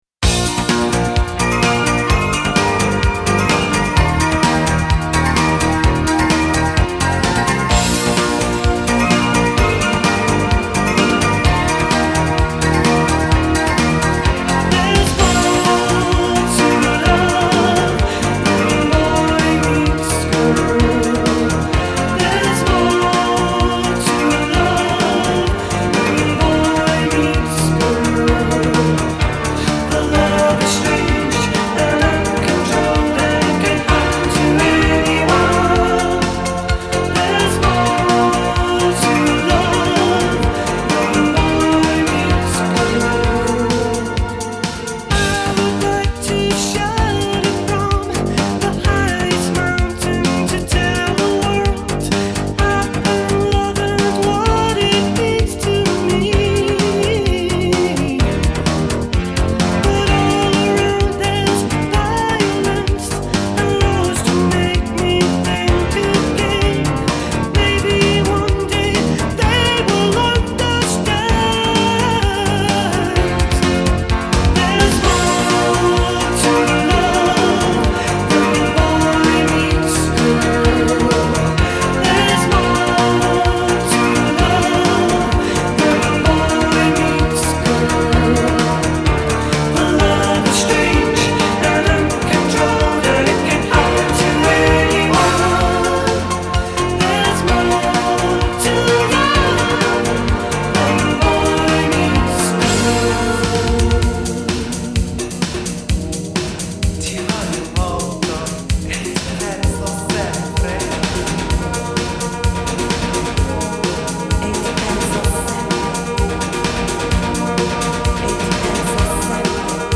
formed the “synth pop” duo